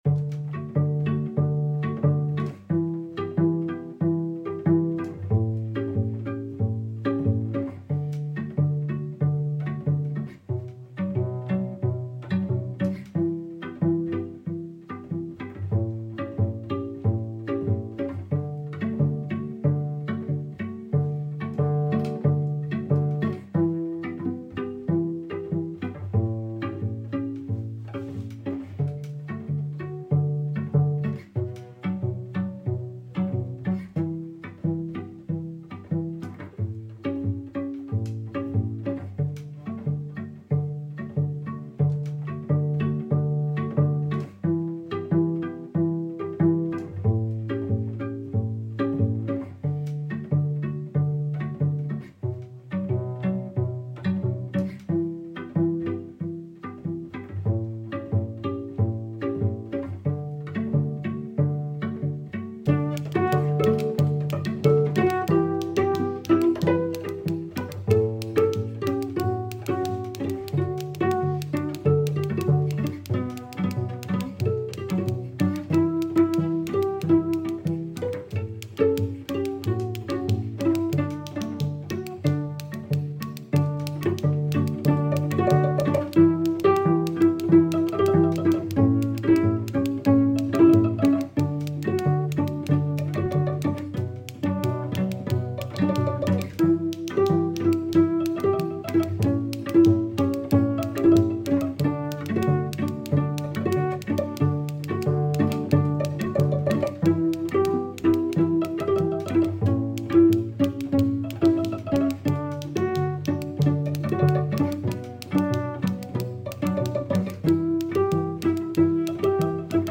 Un chant des Jamaïque repris par Harry Bellafont, mais aussi PowWow et aussi the Birds on a Wire, adaptée pour être chantée par les cyle 3 en terme de tessiture.
C3-JAMAICA-FAREWELL_playback.mp3